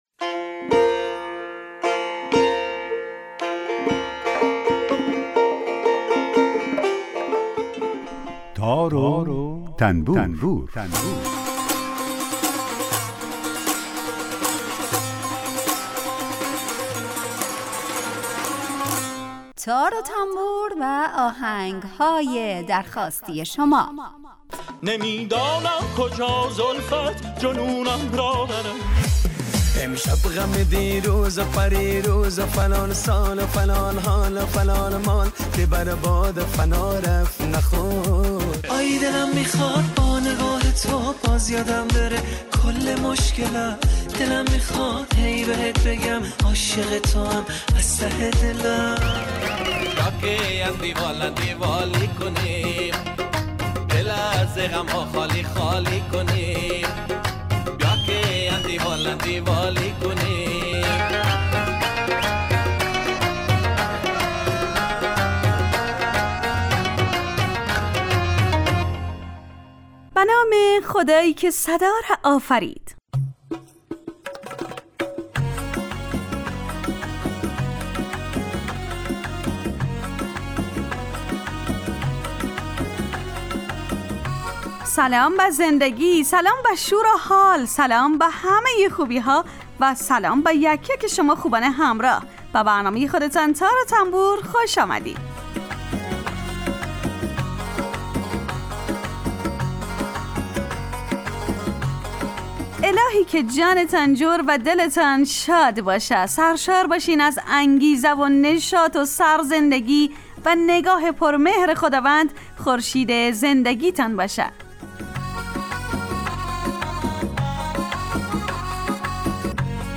آهنگهای درخواستی